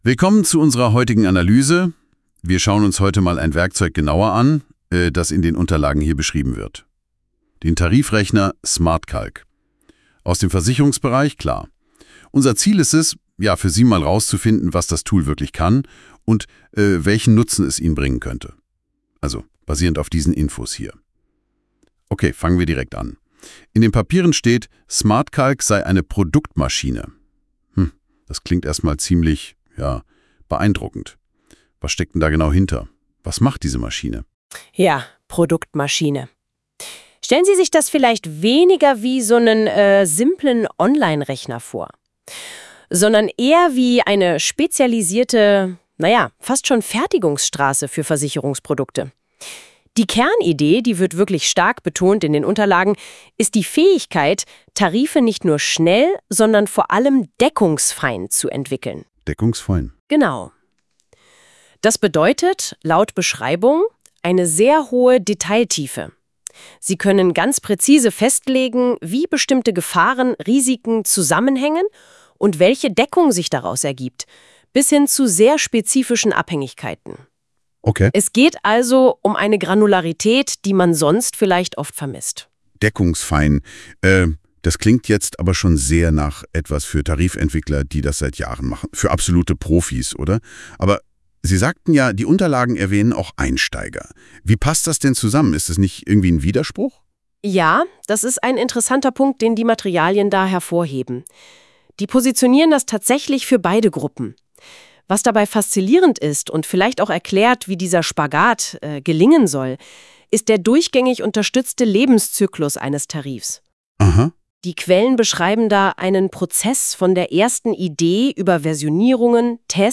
Praxisnah und verständlich erklärt uns die künstliche Intelligenz von Google NotebookLM die Modulgruppen von openVIVA c2.